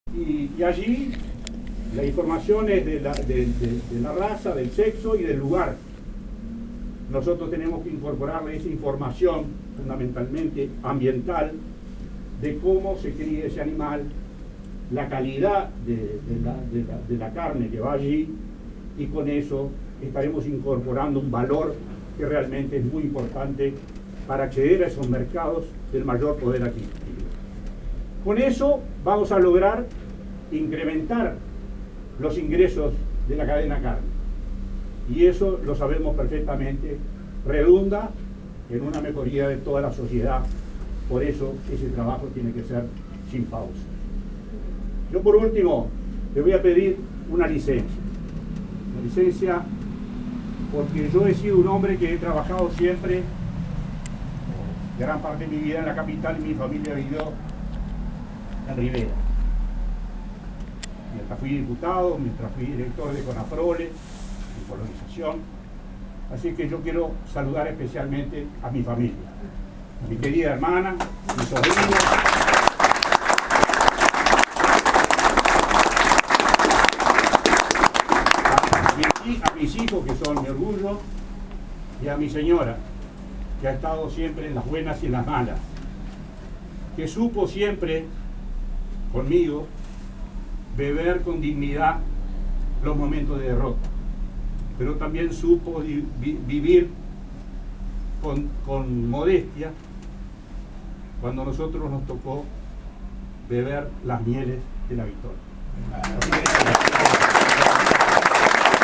En su agradecimiento ante tan nutrido grupo que lo acompañó en este acto privado, el vicepresidente de INAC agradeció especialmente a su familia que ha estado a su lado durante esta trayectoria.